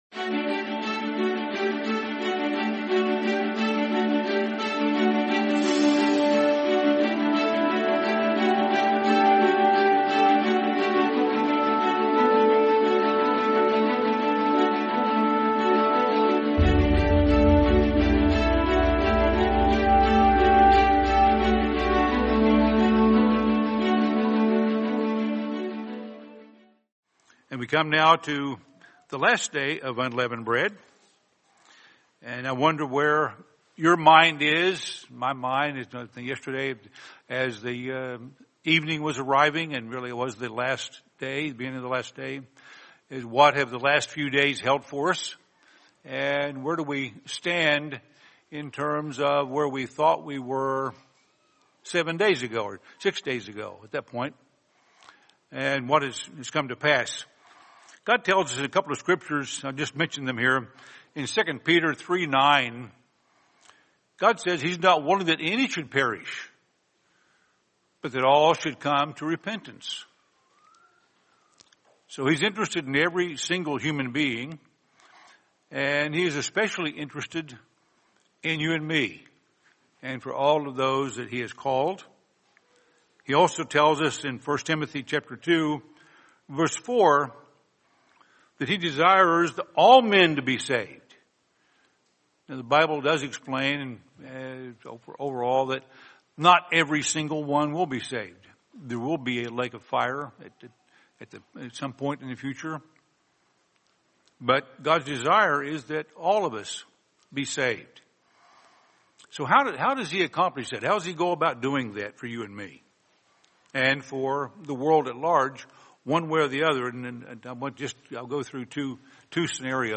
Sermon Every Spiritual Blessing